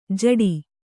♪ jaḍi